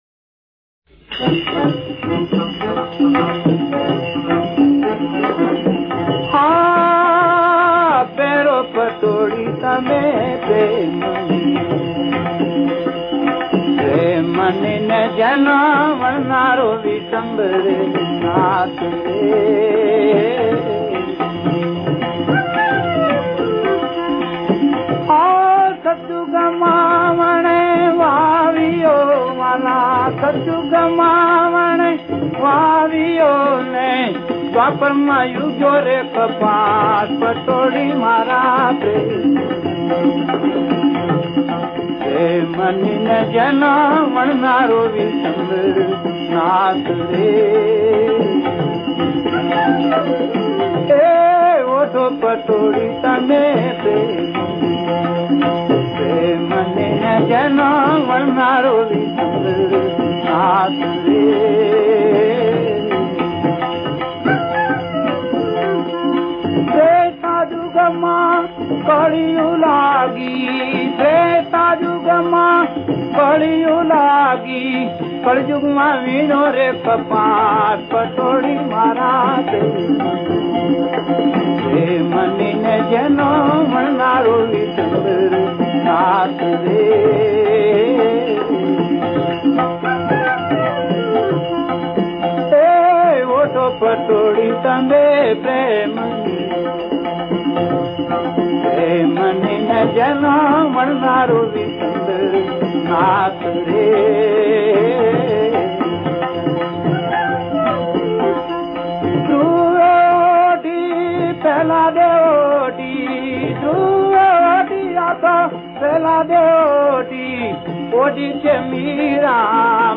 સંતવાણી